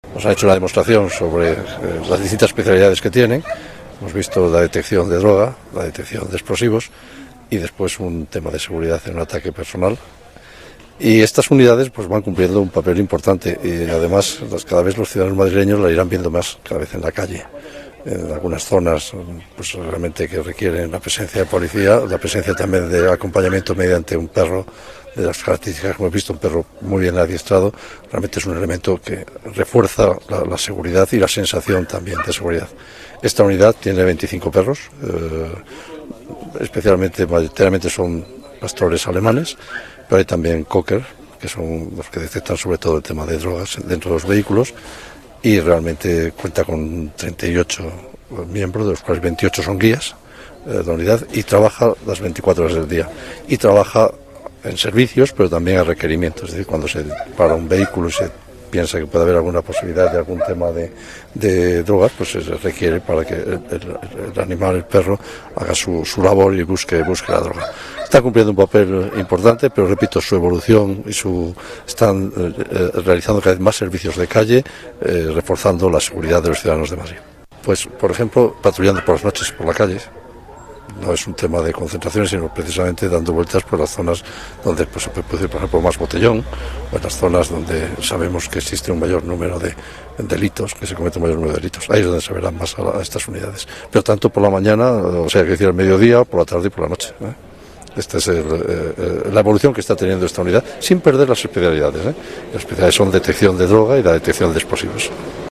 Nueva ventana:Declaraciones delegado de Medio Ambiente y Seguridad, Antonio de Guindos: actuaciones de la Unidad Canina de la Policía Municipal